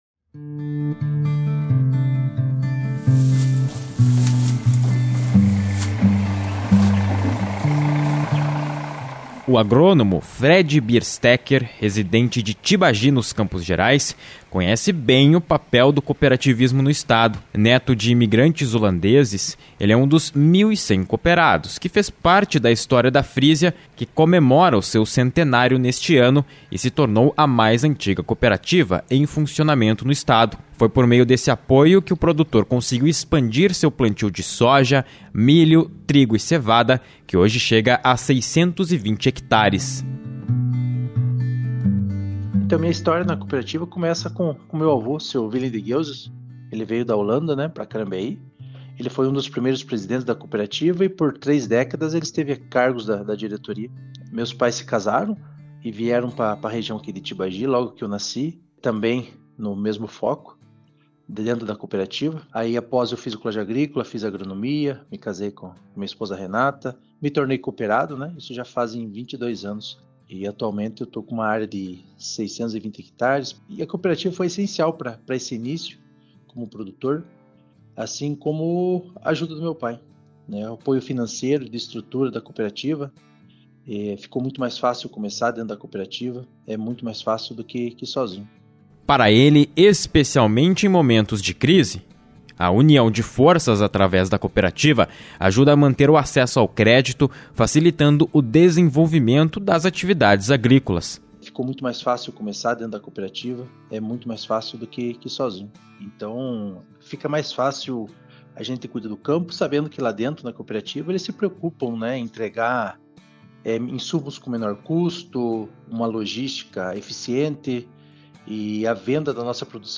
Reportagem 1 – Frísia: 100 anos de história
A Frísia, a cooperativa mais antiga em funcionamento no Paraná chegou ao seu centenário. Representantes da empresa e associados, explicam o que está por trás de um século de sucesso.